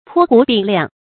陂湖禀量 bēi hú bǐng liáng
陂湖禀量发音